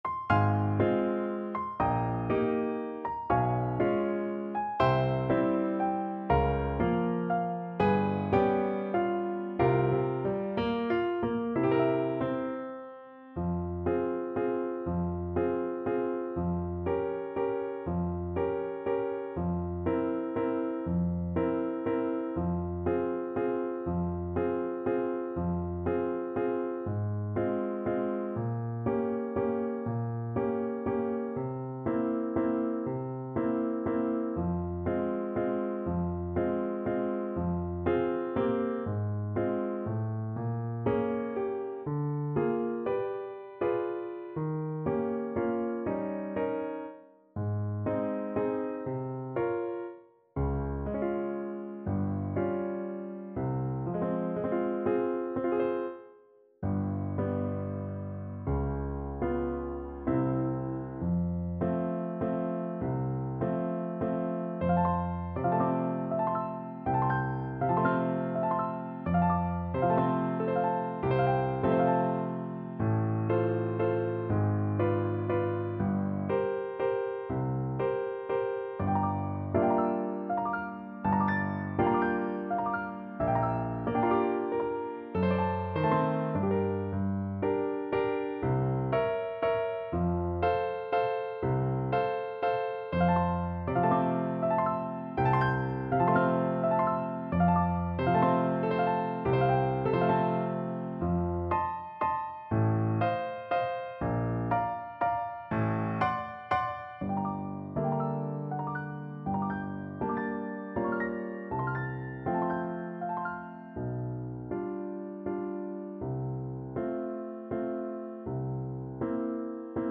~ = 120 Lento